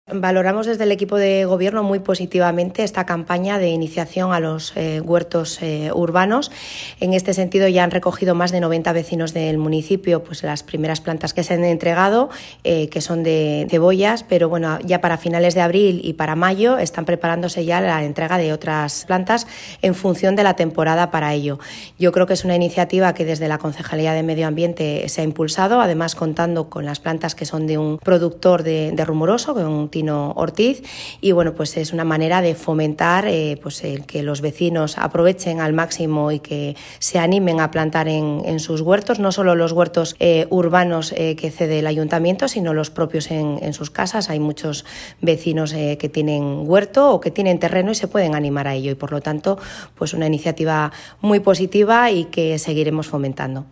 Audio de Rosa Díaz Fernández
Alcaldesa-valora-el-desarrollo-del-reparto-de-cebollas.mp3